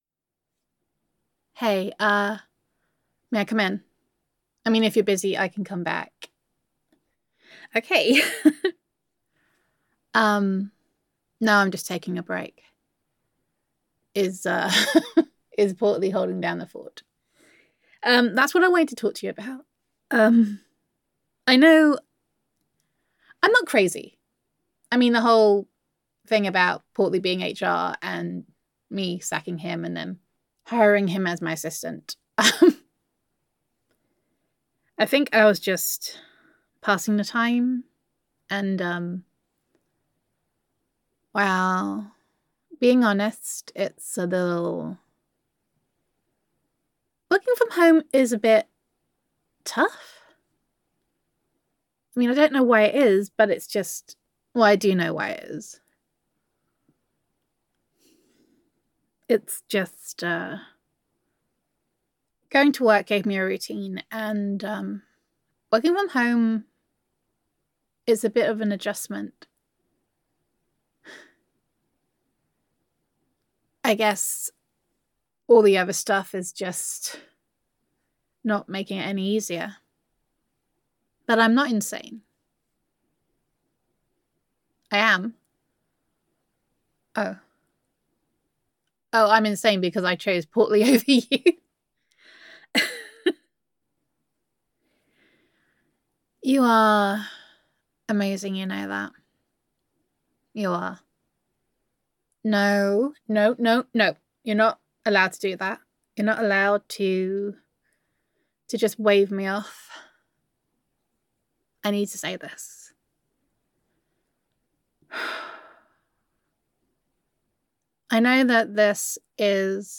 [F4A] Day Three - Getting Used to It [Home With Honey][Girlfriend Roleplay][Self Quarantine][Domestic Bliss][Gender Neutral][Self-Quarantine With Honey]